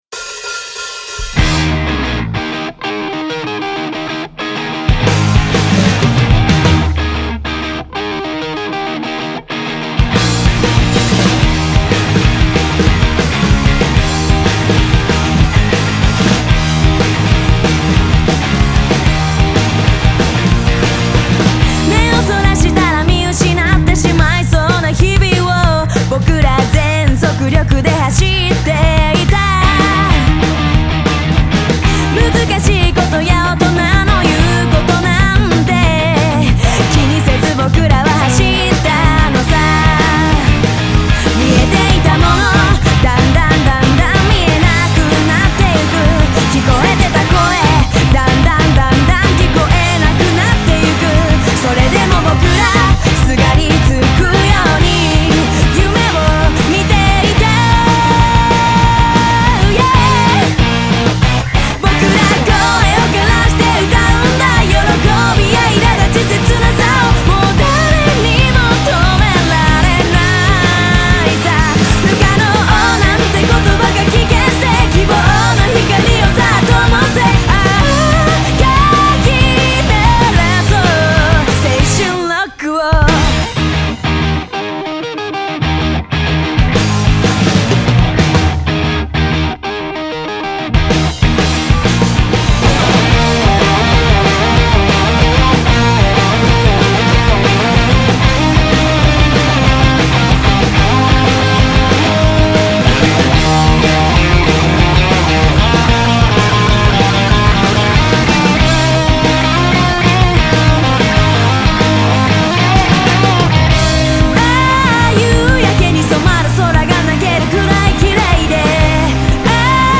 シンプルかつダイナミックなサウンド、ボーカルの真っ直ぐで力強い歌声、熱いライブパフォーマンスは必見！！